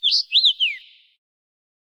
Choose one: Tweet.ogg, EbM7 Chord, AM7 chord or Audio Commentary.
Tweet.ogg